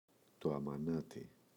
αμανάτι, το [amaꞋnati]